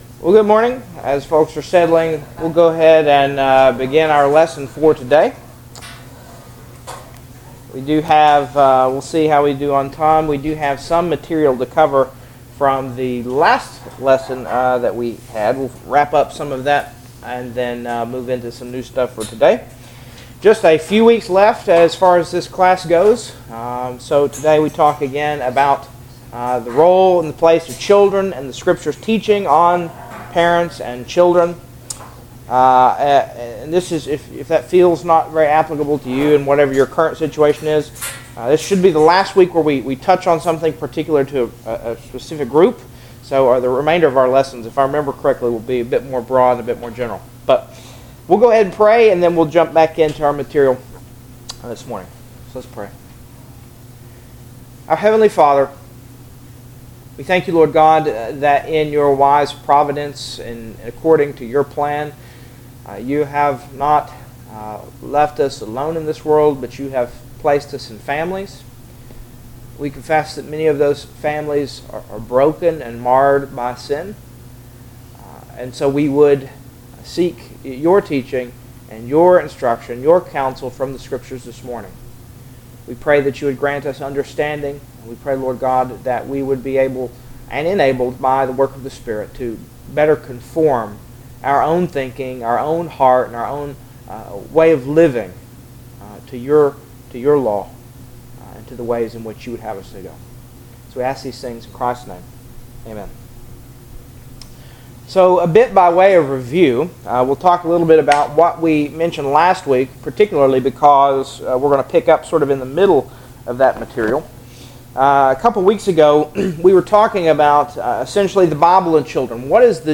Service Type: Sunday School « Sin